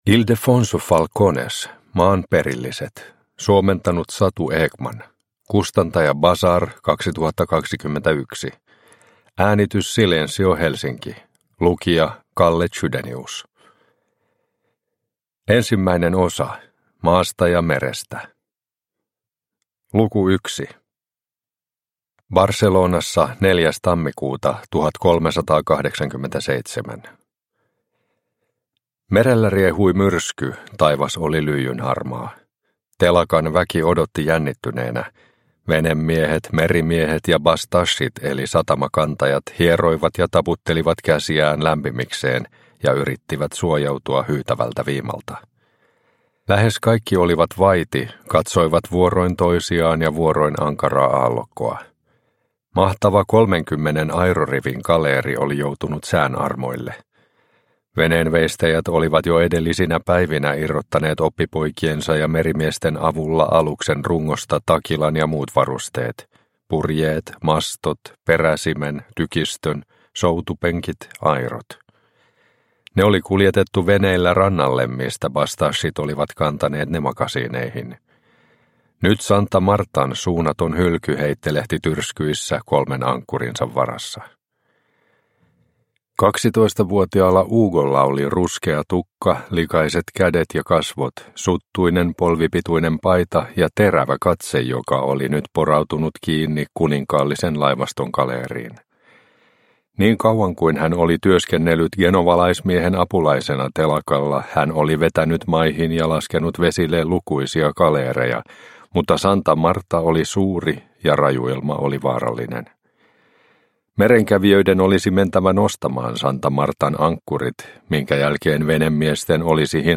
Maan perilliset – Ljudbok – Laddas ner